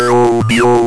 A Go application to generate numbers station like audio output